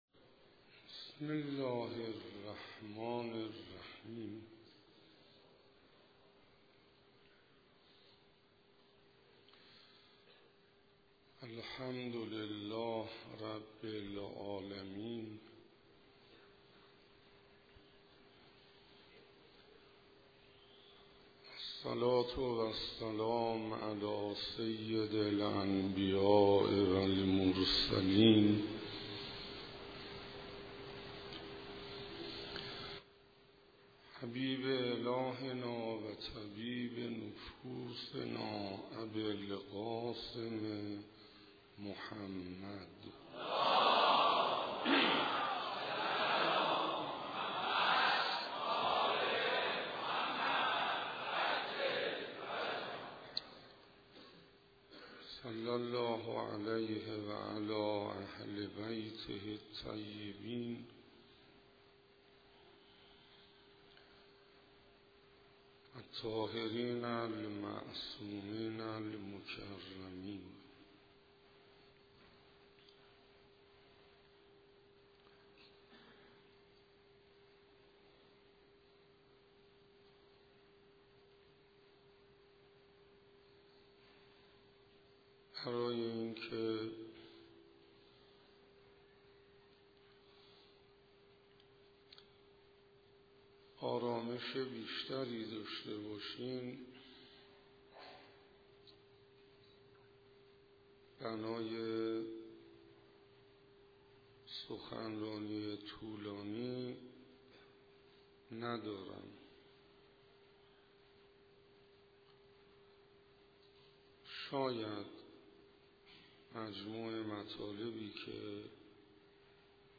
سخنرانی حضرت استاد انصاریان
حسینیه هدایت